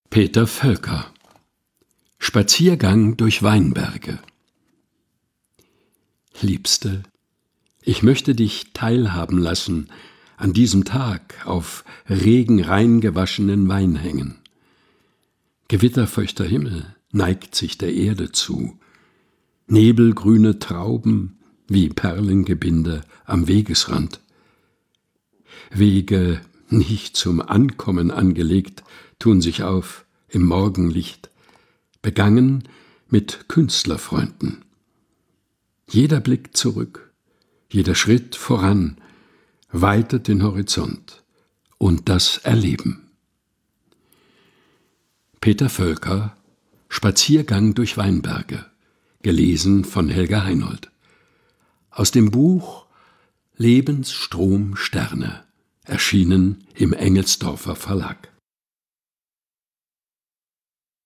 liest sie in seinem eigens zwischen Bücherregalen eingerichteten, improvisierten Studio ein.